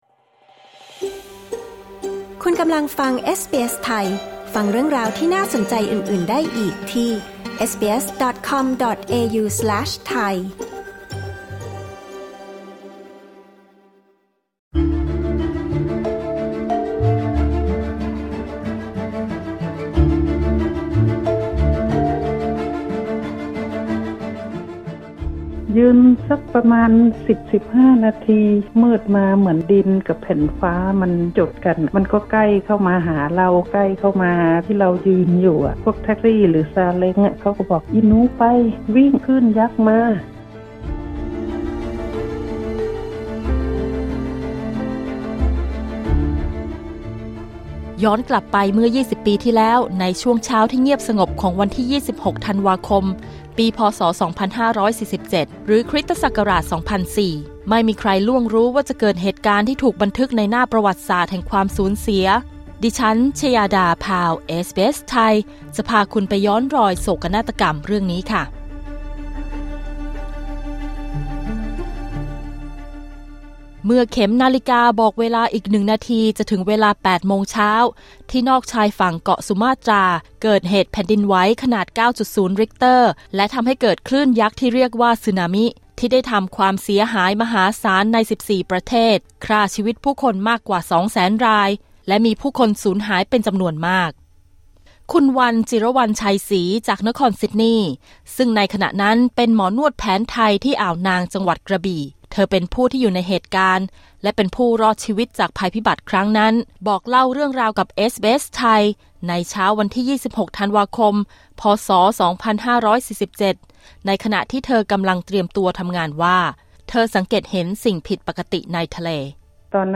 ฟังเสียงคนไทยที่อยู่ในเหตุการณ์สึนามิ วันที่ 26 ธันวาคม ปี พ.ศ 2547 ทั้งวินาทีวิ่งหนีคลื่นยักษ์ การช่วยค้นหาร่างผู้เสียชีวิต และ ความโกลาหลหลังคลื่นยักษ์พัดผ่านไป ในรายงานพิเศษ 'รำลึก 20 ปี สีนามิในประเทศไทย'